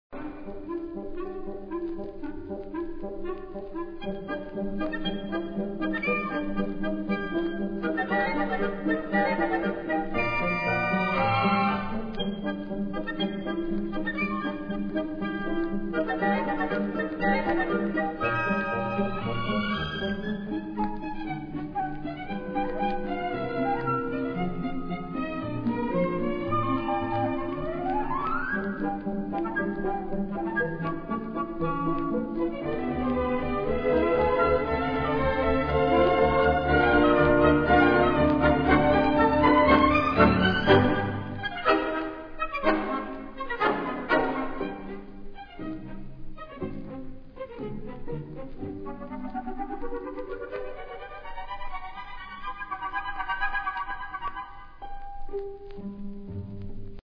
Interlude music